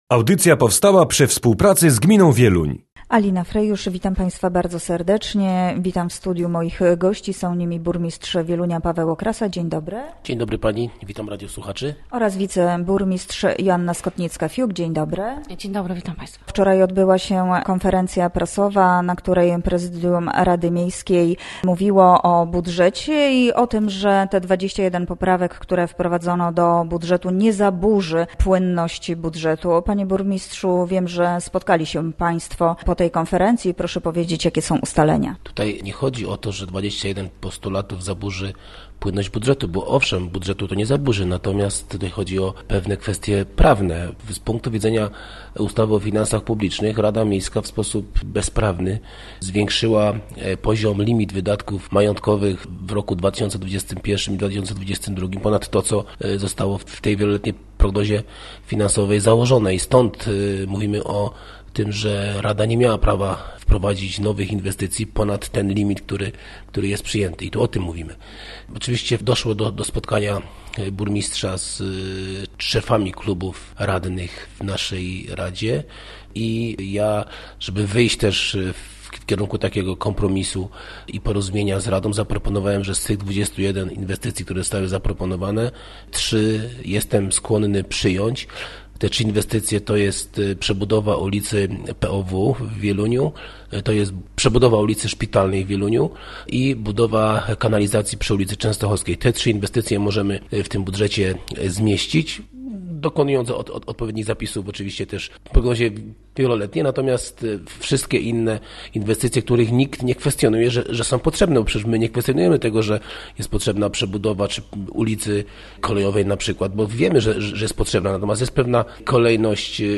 Gośćmi Radia ZW byli burmistrz Wielunia, Paweł Okrasa i wiceburmistrz, Joanna Skotnicka-Fiuk – Radio Ziemi Wieluńskiej